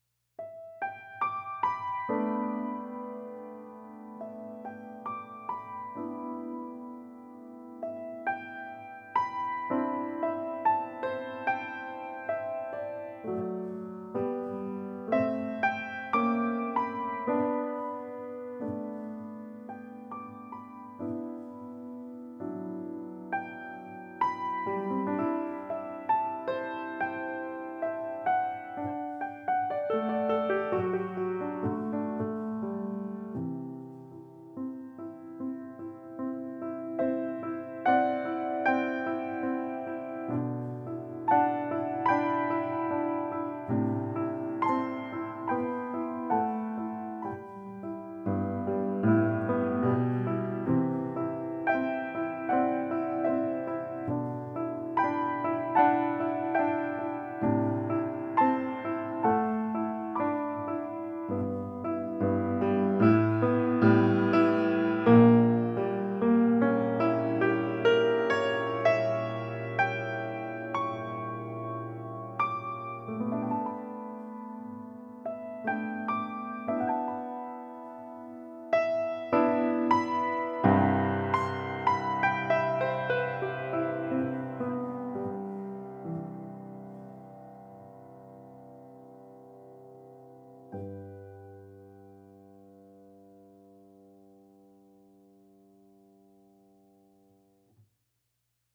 Piano, Música pedagogica